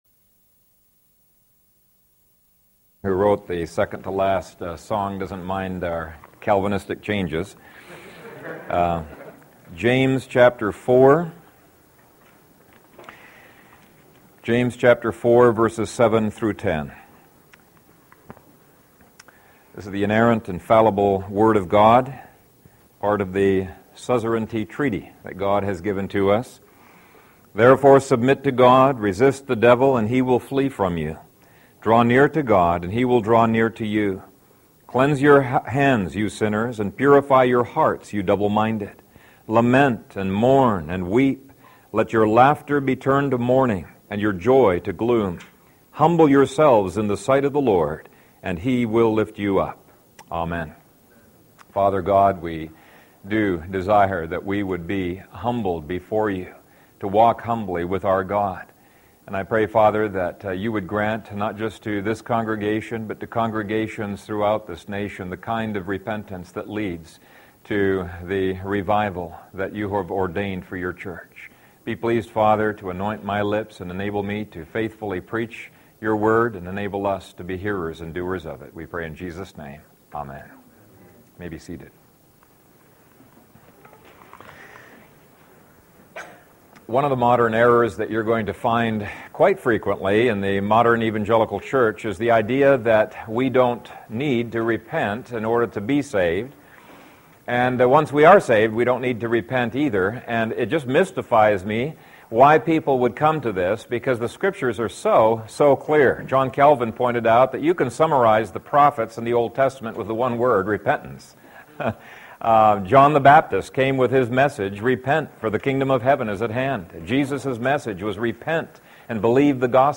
The Life of True Repentance | SermonAudio Broadcaster is Live View the Live Stream Share this sermon Disabled by adblocker Copy URL Copied!